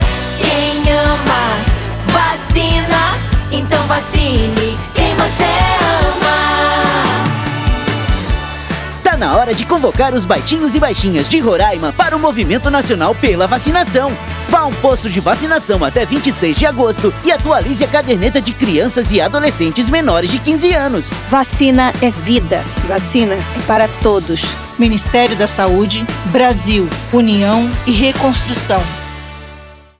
Áudio - Spot 30seg - Campanha de Multivacinação em Roraima - 1,74mb .mp3